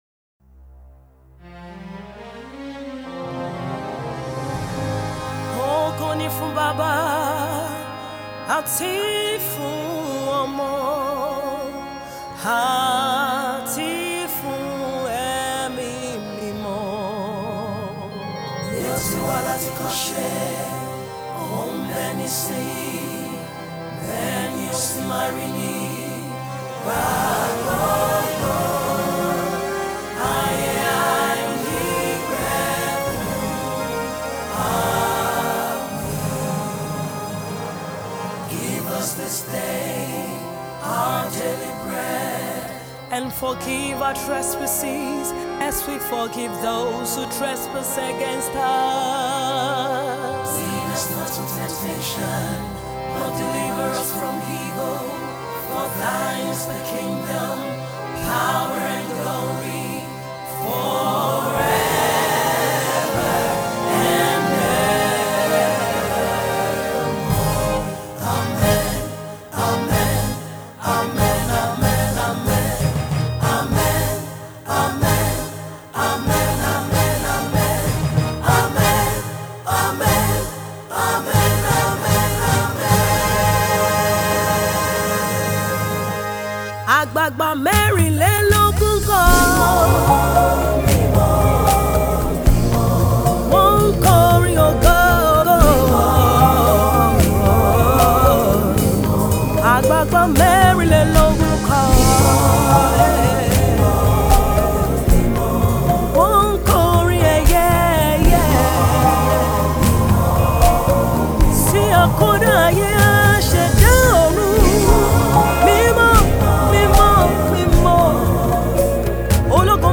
spirit-filled brand new single of intimate worship